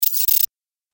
جلوه های صوتی
دانلود صدای ربات 52 از ساعد نیوز با لینک مستقیم و کیفیت بالا